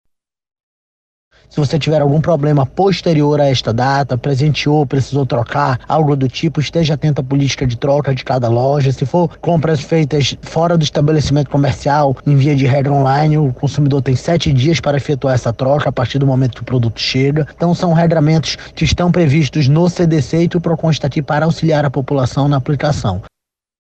Como explica o diretor-presidente do Procon Amazonas, Jalil Fraxe.
Sonora-2-Jalil-Fraxe-diretor-presidente-do-Procon-Amazonas.mp3